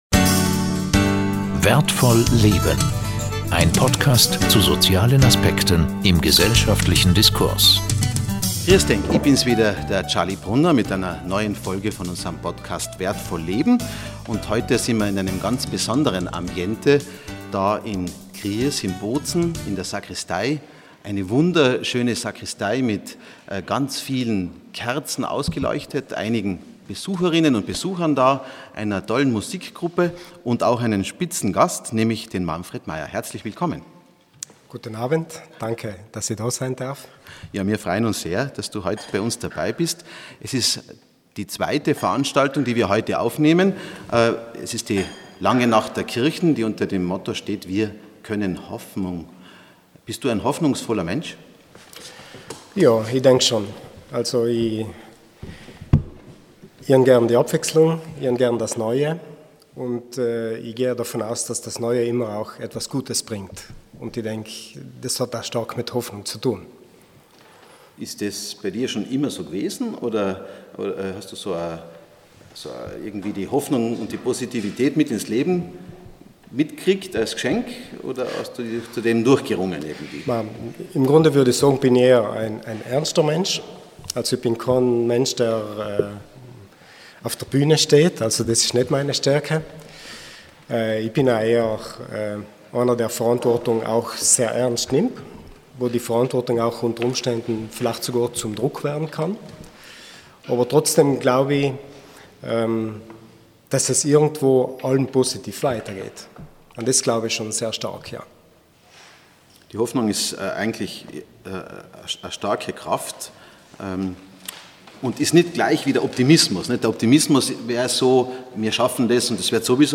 Eine Podcastaufnahme an einem besonderen Ort mit einem besonderen Gast.
Anlässlich der Langen Nacht der Kirchen aus der Sakristei der Stiftspfarrkirche von Gries